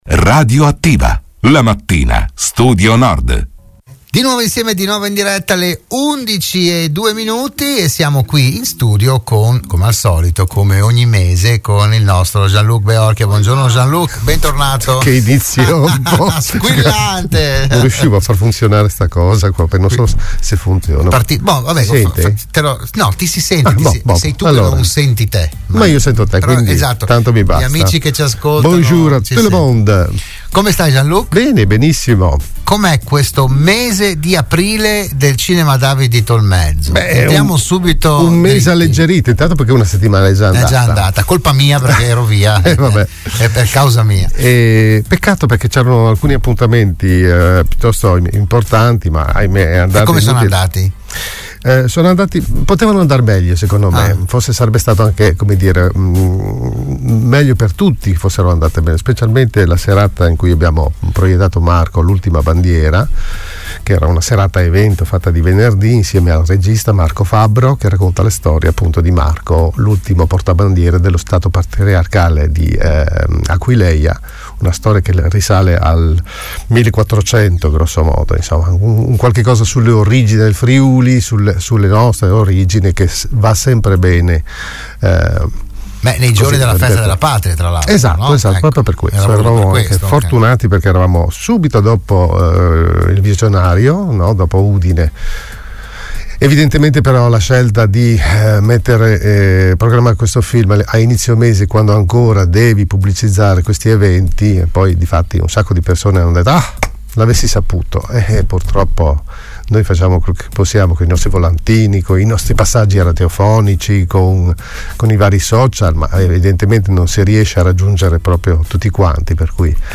“RadioAttiva“, la trasmissione di Radio Studio Nord